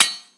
surface_metal1.mp3